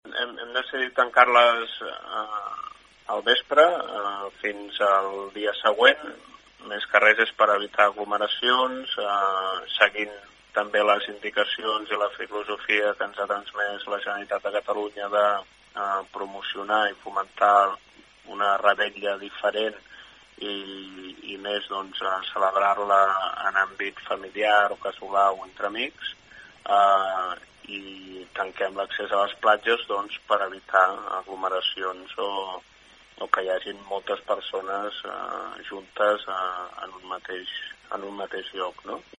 Joan Mercader, alcalde de Malgrat de Mar, afirma que es vol evitar aglomeracions a les platges la nit de la revetlla i que, si finalment no es fa cap celebració popular prop del mar, tampoc es pugui accedir a les platges per coherència. N’ha parlat en declaracions a aquesta emissora.